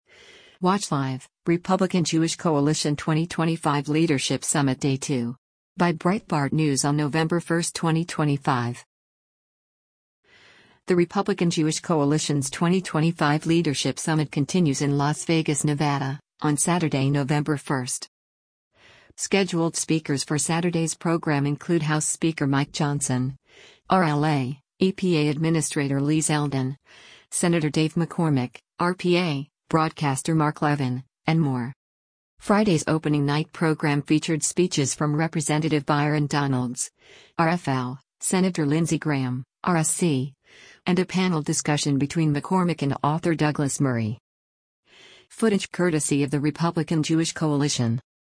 The Republican Jewish Coalition’s 2025 Leadership Summit continues in Las Vegas, Nevada, on Saturday, November 1.
Scheduled speakers for Saturday’s program include House Speaker Mike Johnson (R-LA), EPA Administrator Lee Zeldin, Sen. Dave McCormick (R-PA), broadcaster Mark Levin, and more.